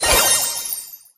sandy_atk_01.ogg